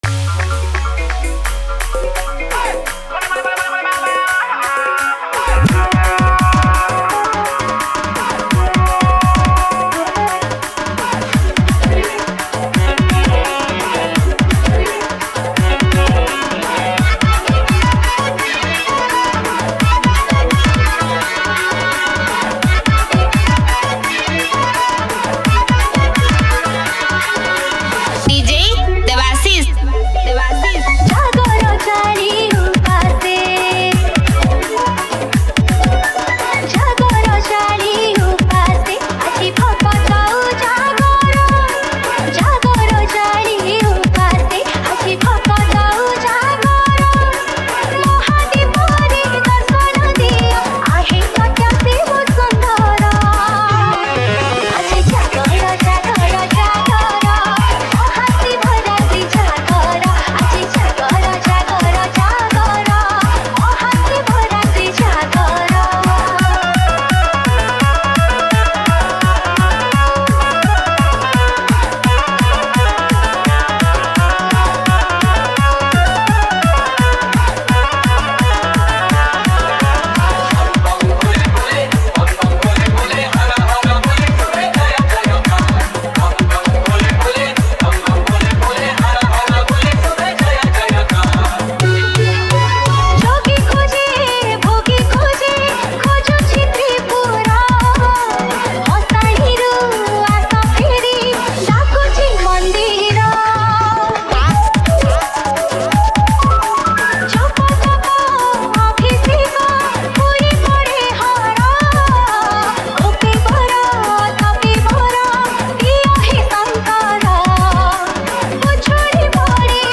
Shivratri Special DJ Remix Songs